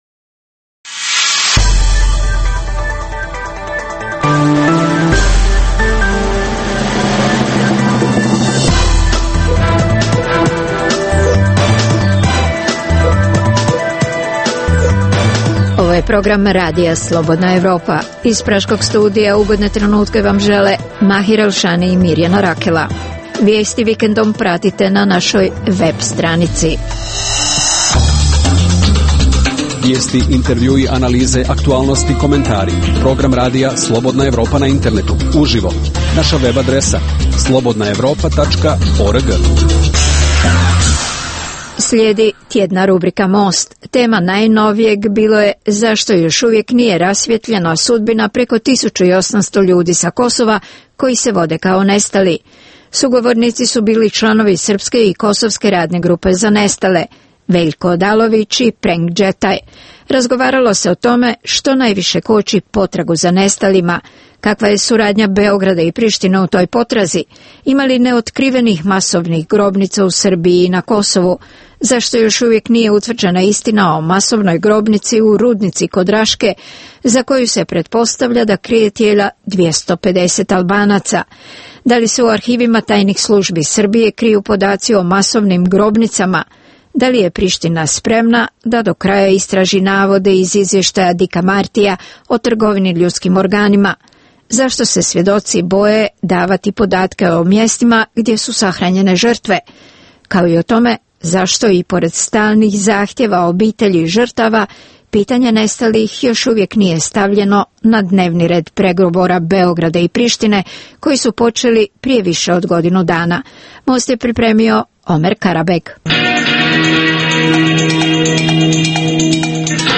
Tema Mosta ovaj put je: zašto još nije rasvetljena sudbina preko 1800 ljudi sa Kosova koji se vode kao nestali. Diskutuju članovi srpske i kosovske radne grupe za nestale - Veljko Odalović i Prenk Đetaj.